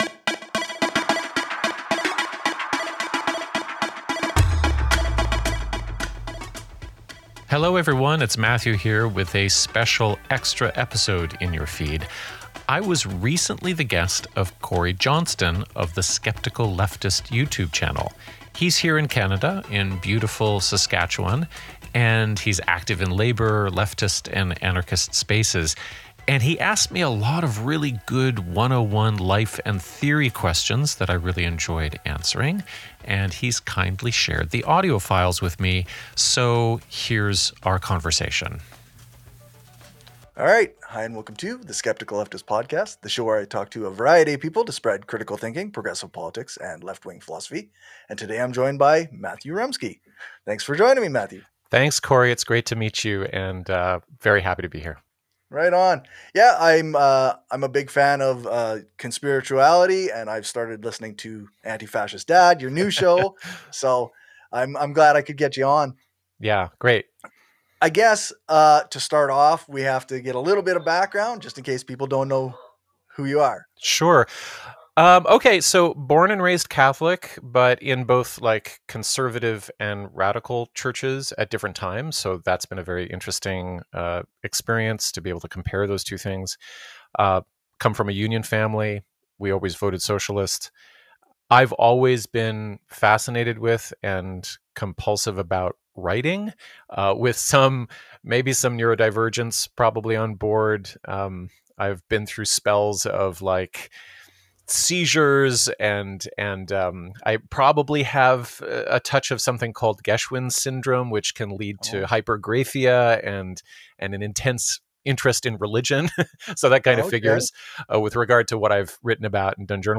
Just a coupla antifascist Canadian dads having a chat about stuff.